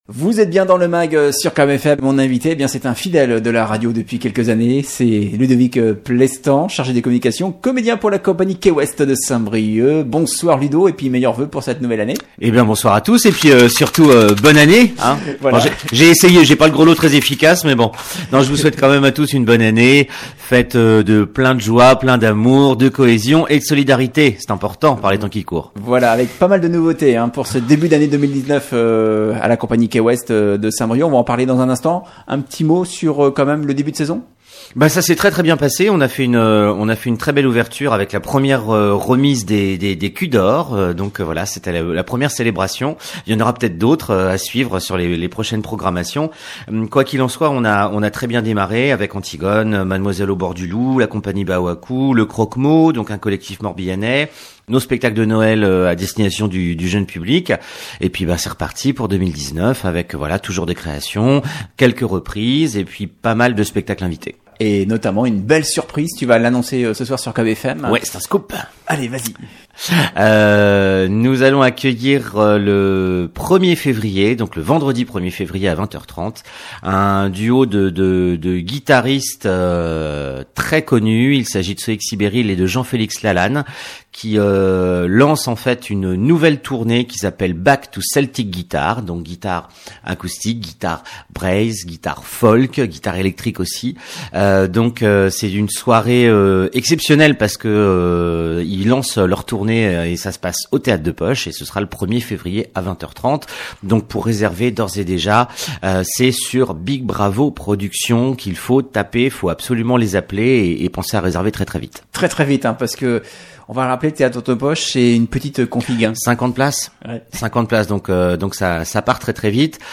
Invité du Mag hier soir